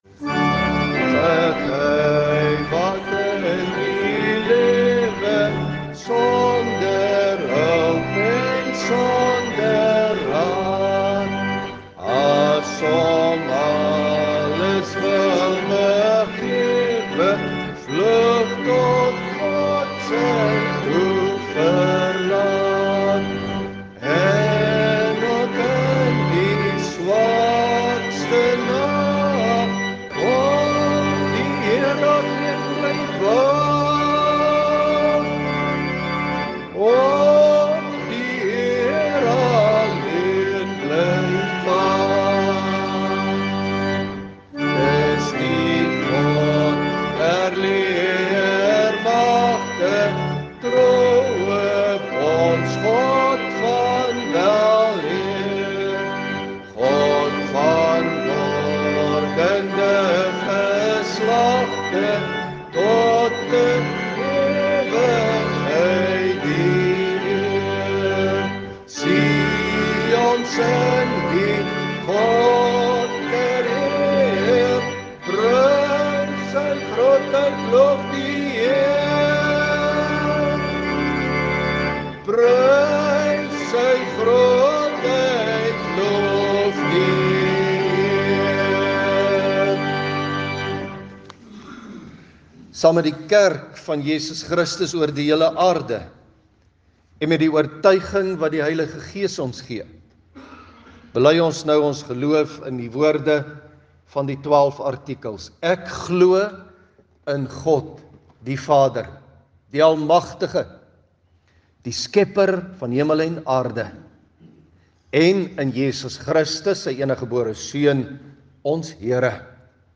Klankbaan Luister na die preek.